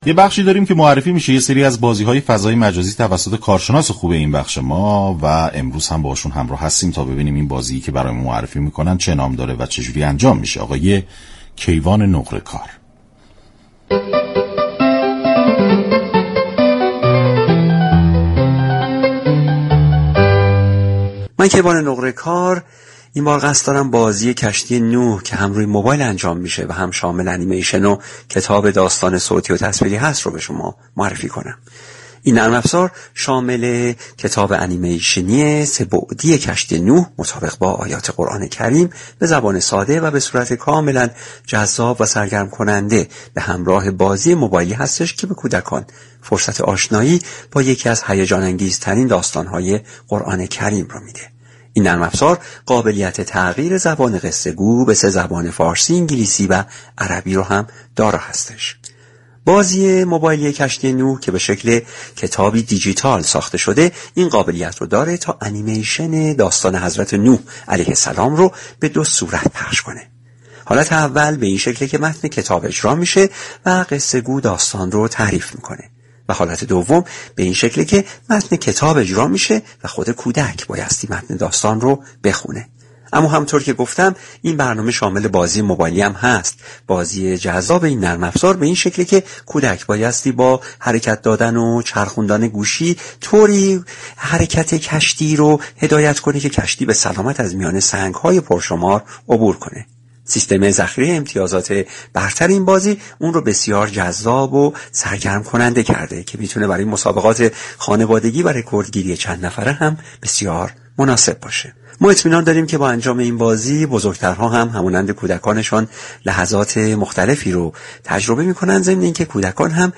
شما می توانید از طریق فایل صوتی ذیل شنونده بخشی از برنامه "گلخونه" رادیو ورزش كه به توضیح درباره نحوه اجرای این بازی می پردازد؛ باشید.